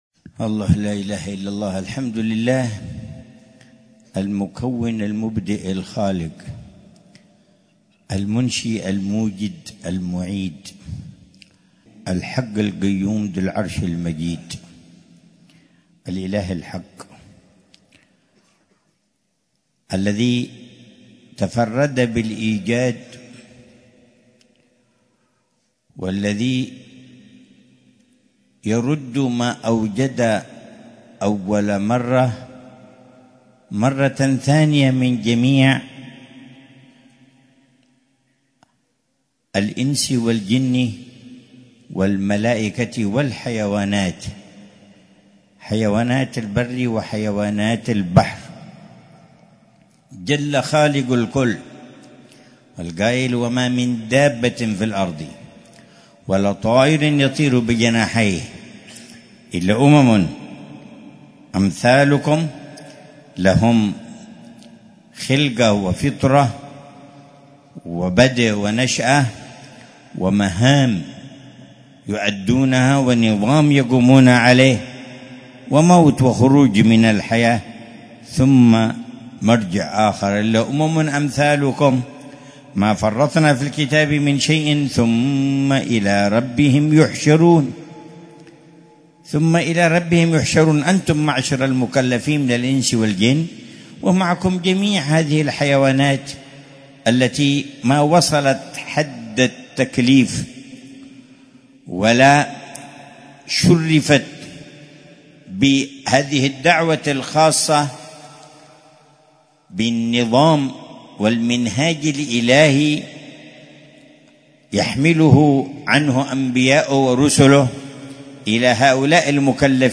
مذاكرة العلامة الحبيب عمر بن محمد بن حفيظ في الجلسة السنوية لحارتي عيديد والخليف، بمدينة تريم، ليلة السبت 4 رجب الأصب 1446هـ بعنوان: